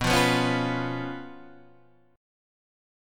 B Suspended 2nd Flat 5th